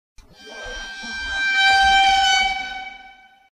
gordon ramsey violin
gordon-ramsey-violin.mp3